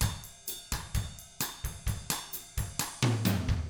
129BOSSAF4-R.wav